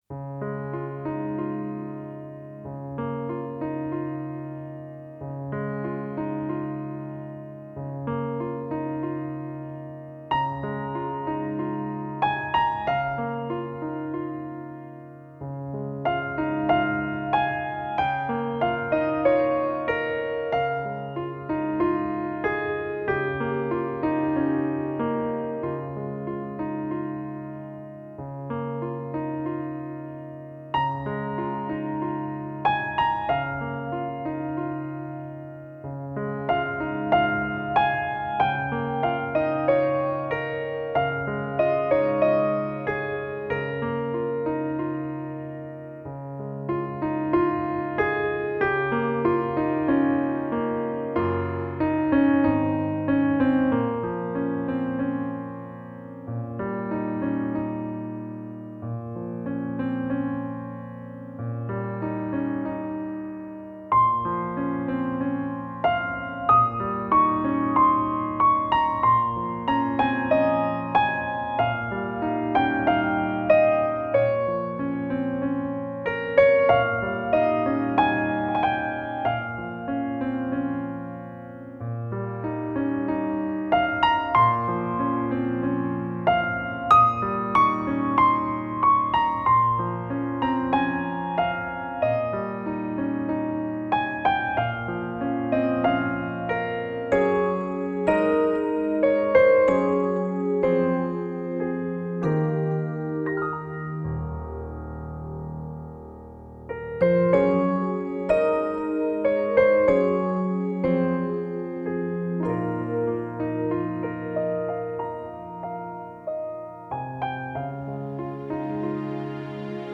有一种琴声，如呢喃似轻诉， 有一种心情，如飞行似遨游。
最清澈动人的音乐旋律，